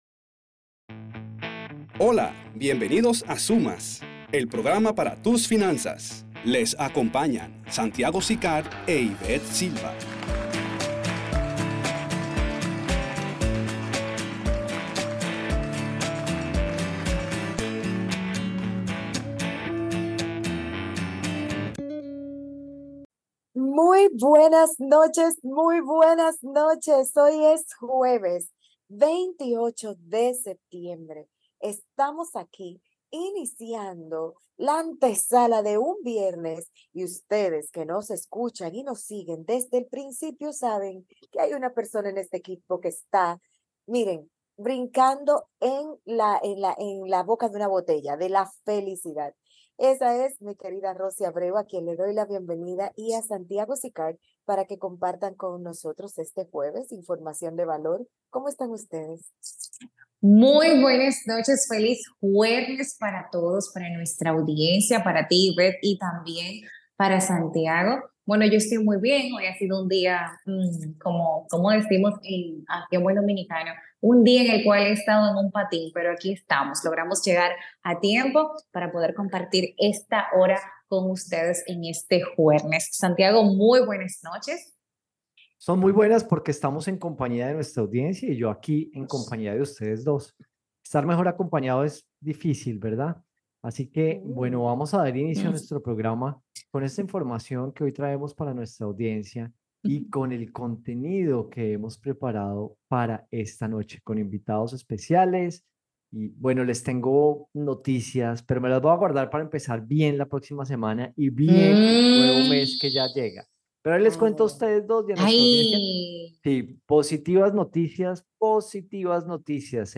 Escucha todo nuestro programa de rqadio de hoy.